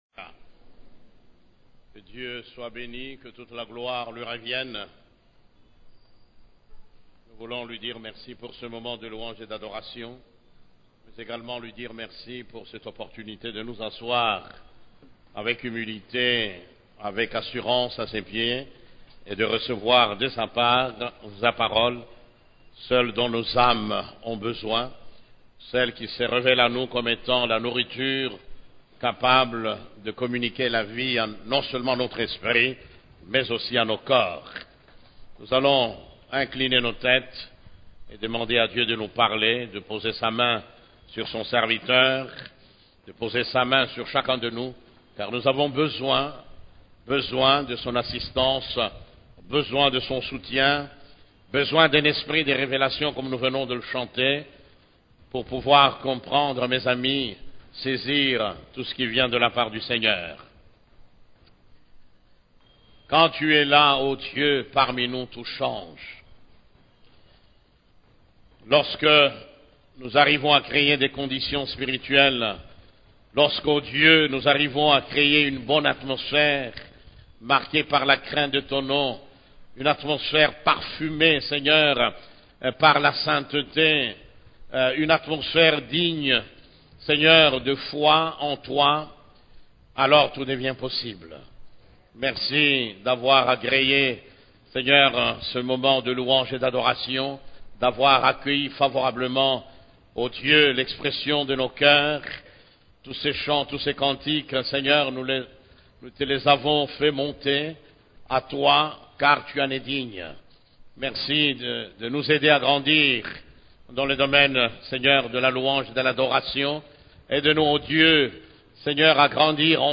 CEF la Borne, Culte du Dimanche